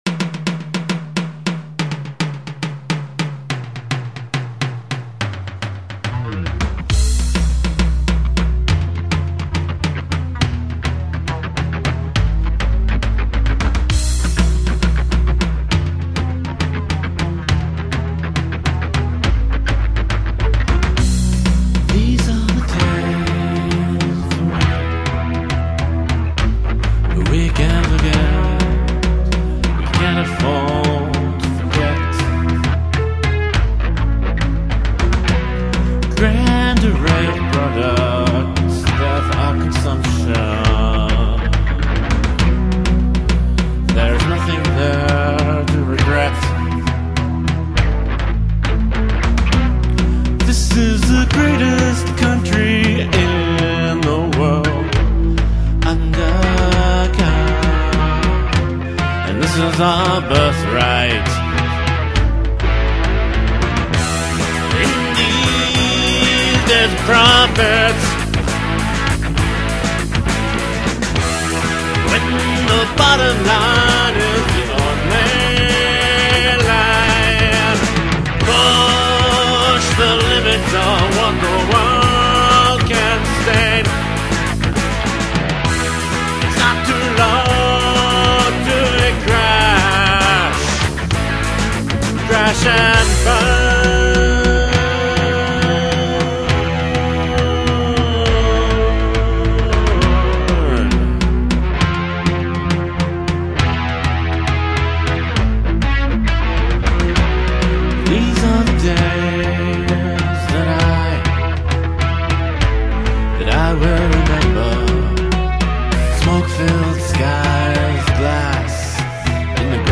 Bass Guitar and lead vocals
Drums